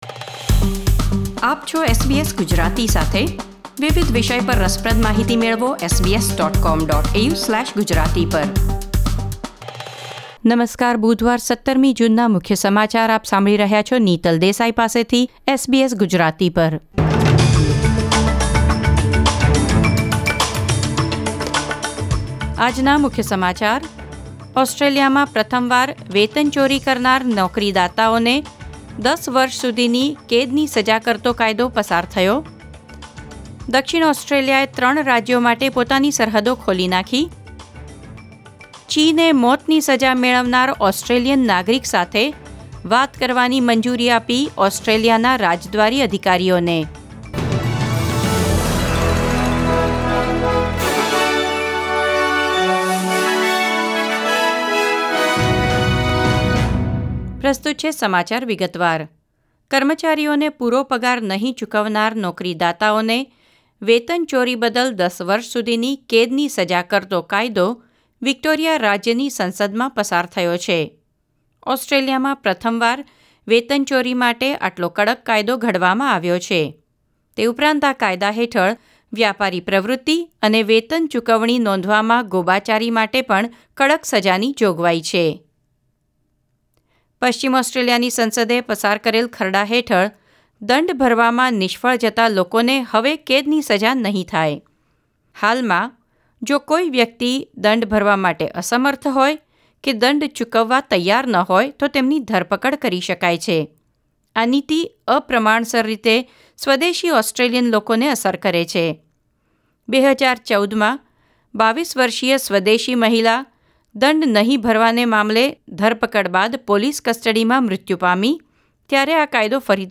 ૧૭ જૂન ૨૦૨૦ ના મુખ્ય સમાચાર